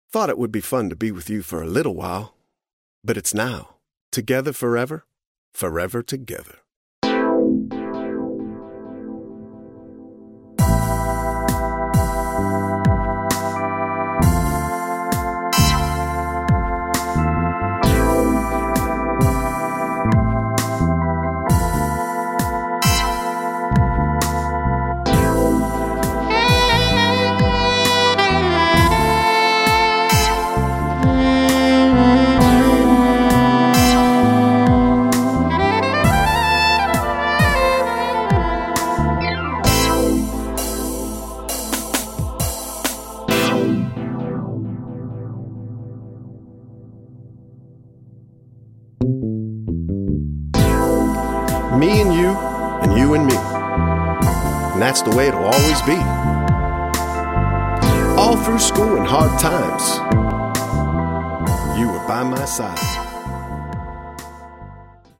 Light rap, a playful testimony, a tribute in soulful blues.